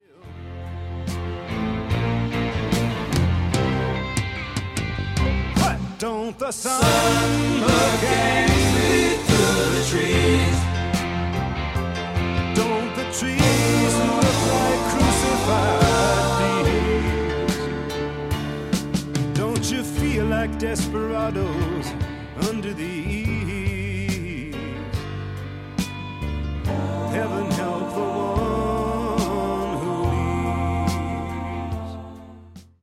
Rock 70s 80s Music